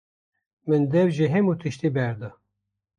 Pronounced as (IPA) /dɛv/